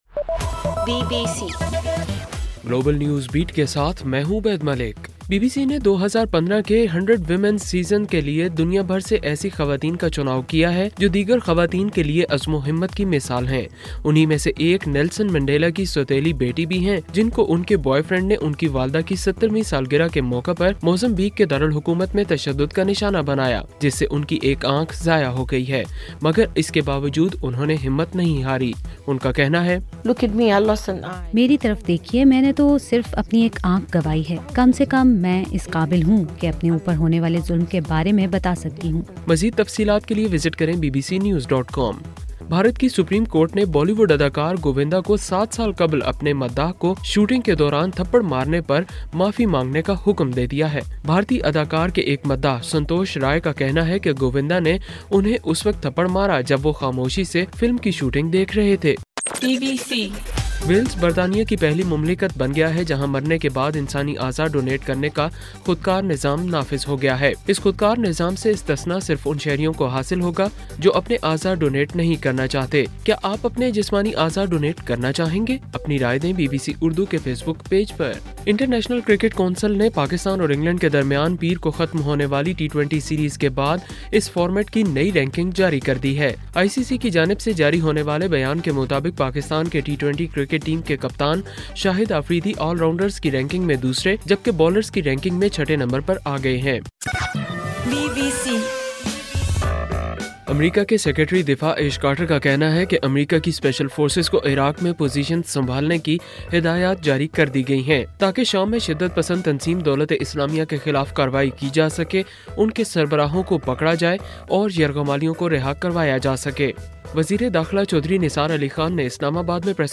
دسمبر 1: رات 12 بجے کا گلوبل نیوز بیٹ بُلیٹن